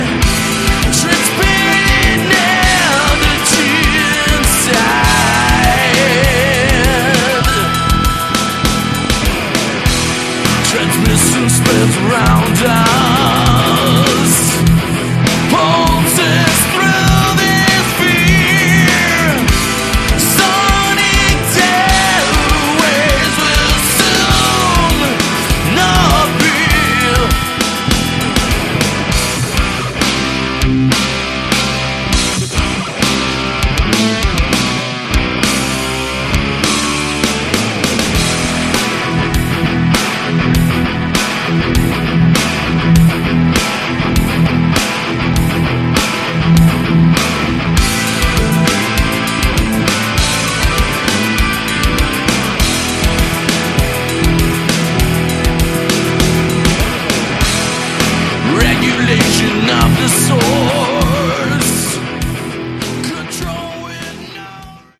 Category: Melodic, Progressive
vocals, guitars, bass, drum programming
bass and Moog pedals